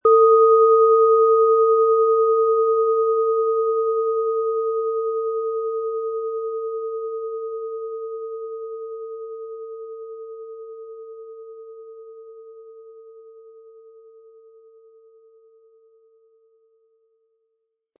Planetenschale® Werde frei und unbefangen & Meditationen mit beruhigten Gedanken mit Thetawellen, Ø 11,2 cm, 180-260 Gramm inkl. Klöppel
Thetawelle
Diese Planeten-Klangschale Thetawelle wurde in alter Tradition von Hand hergestellt.
Der richtige Schlegel ist kostenlos dabei, der Klöppel lässt die Klangschale voll und angenehm anklingen.